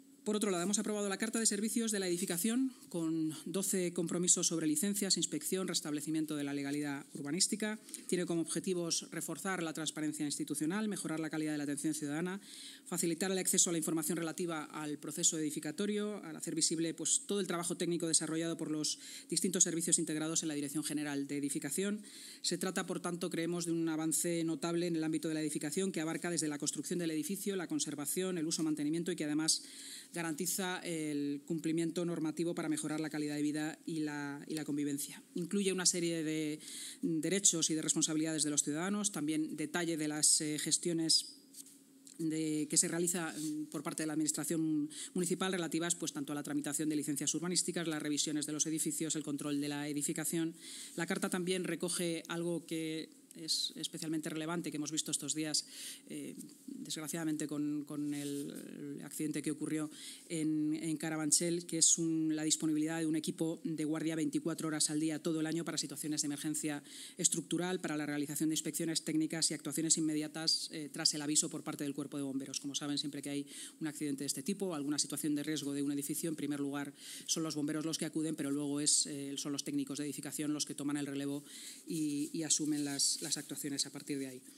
Con esta iniciativa, el Ayuntamiento pasa a contar con una nueva herramienta estratégica de gestión orientada a la mejora continua, la rendición de cuentas y la excelencia en la prestación de servicios públicos relacionados con la edificación en la ciudad de Madrid, según ha explicado en rueda de prensa la vicealcaldesa y portavoz municipal, Inma Sanz.